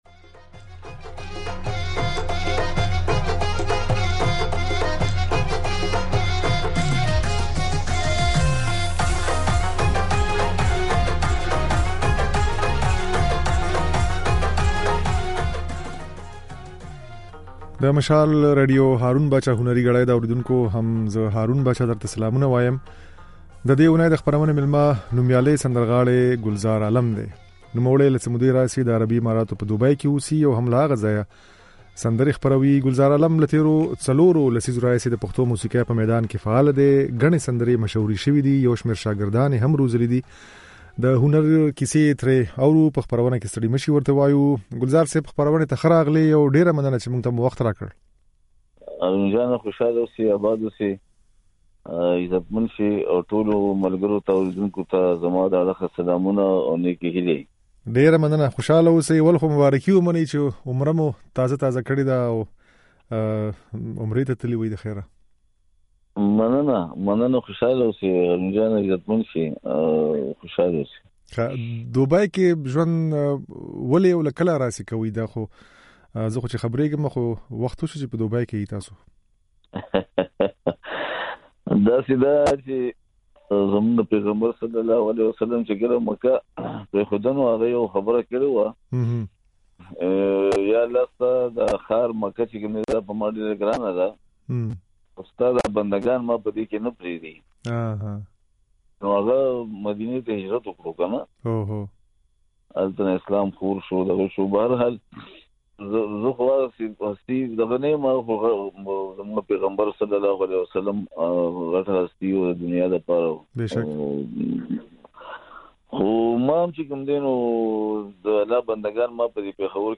د دې اونۍ د "هارون باچا هنري ګړۍ" خپرونې مېلمه نوميالی سندرغاړی ګلزار عالم دی.
د ګلزار عالم دا خبرې او ځينې سندرې يې په خپرونه کې اورېدای شئ.